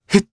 Arch-Vox_Attack1_jp.wav